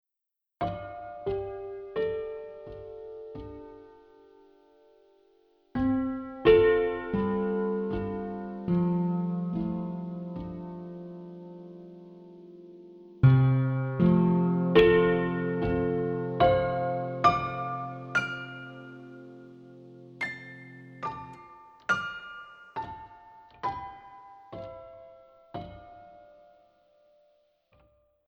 Für nostalgische Atmosphären gibt es eine Vinyl- und eine Aged-Version; Letztere hören Sie hier: